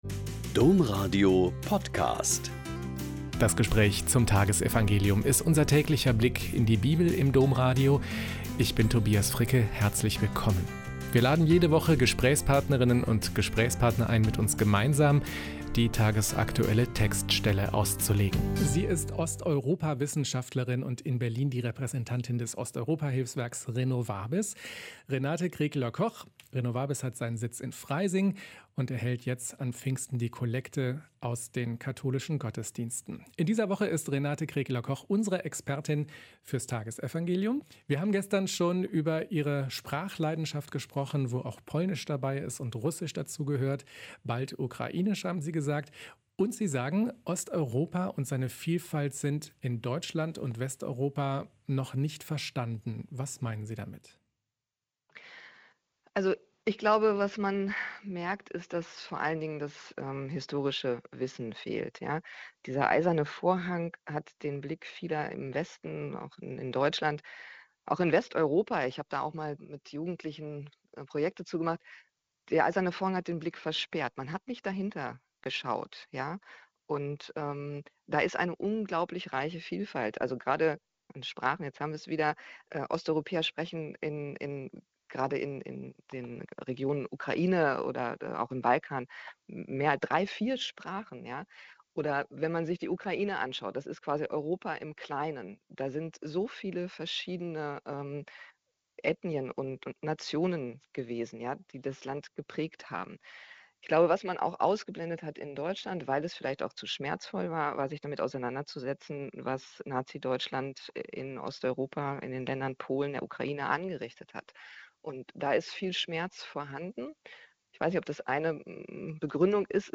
Joh 15,14-16a.18-20 - Gespräch